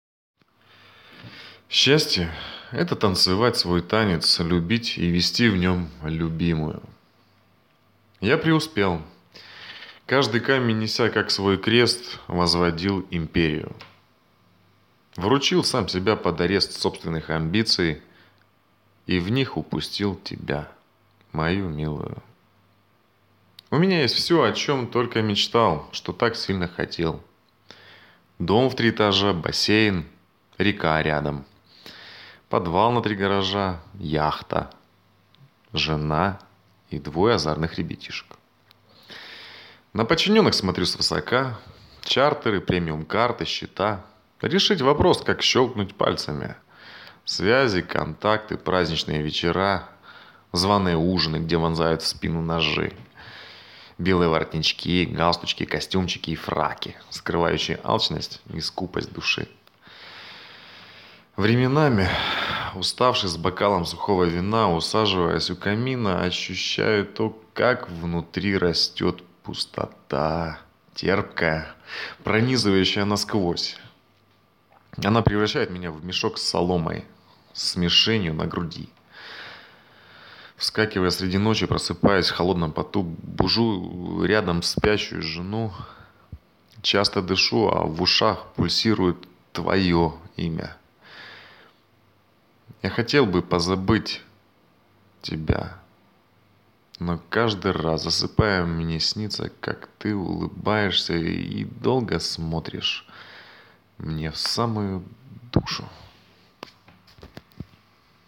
Чоловіча
Баритон